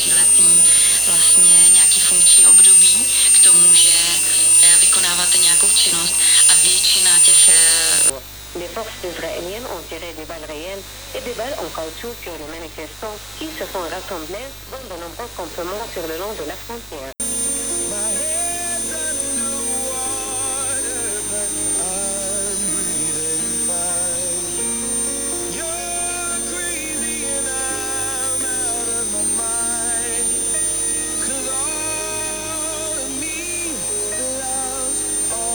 Bei der Inbetriebnahme wurde festgestellt dass lokale 50 Hz-Felder den Verstärker schon übersteuern und es notwendig werden könnte auch den Rahmenkreis selektiv zu gestallten und mit einem Drehkondensator abstimmbar zu machen. Aber auch ohne diese Maßnahme war mit dem >Detektorempfänger< schon ein guter Empfang mehrerer Sender auf Lang- und Mittelwelle möglich was im Mitschnitt rechts zu hören ist.